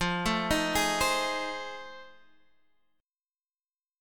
F 7th Sharp 9th